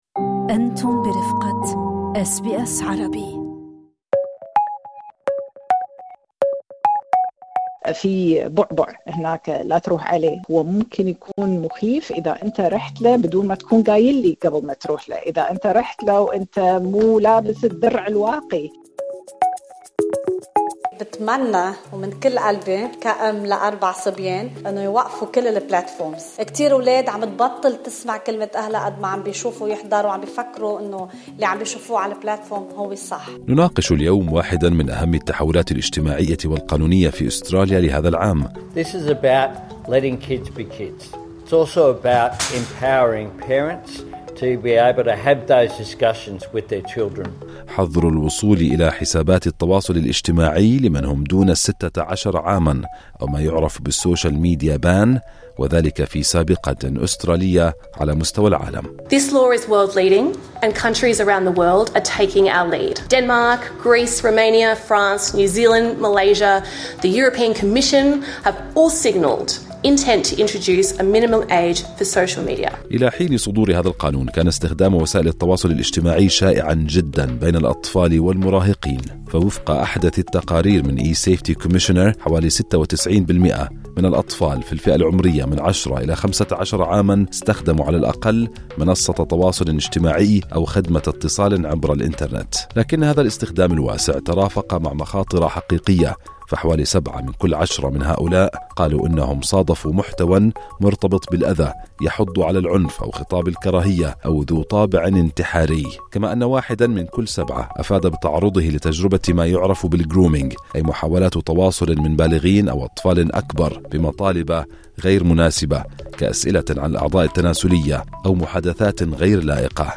حظر وسائل التواصل الاجتماعي في أستراليا: خبيرة تشرح الآلية المستخدمة والتطبيقات التي يشملها